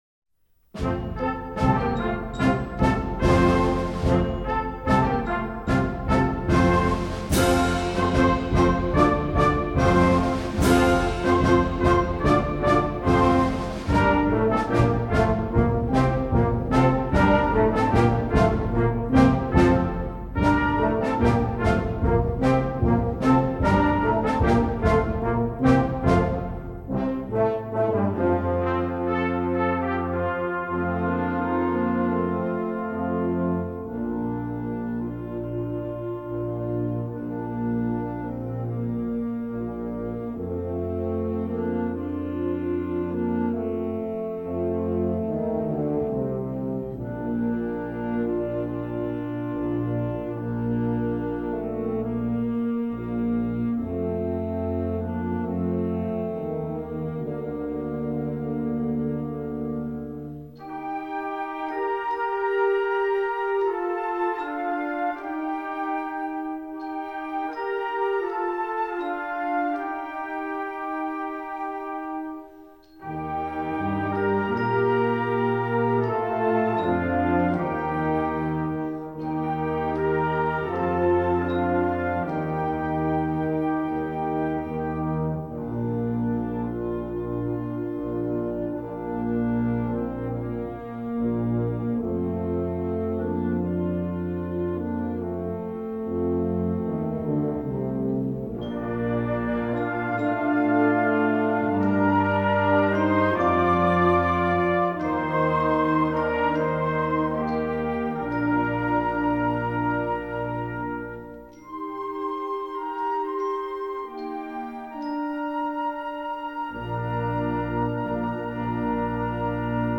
Voicing: Dixieland